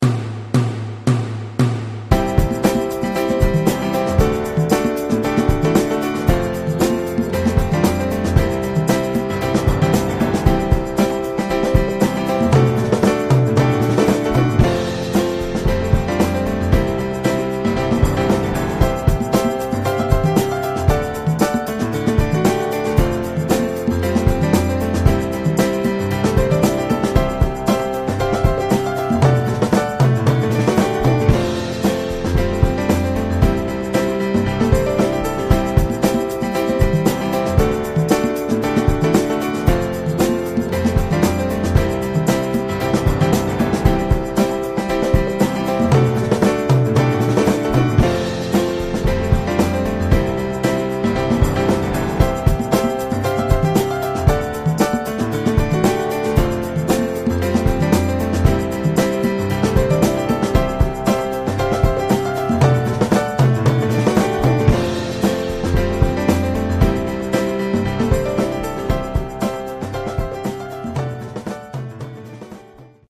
Backing Track http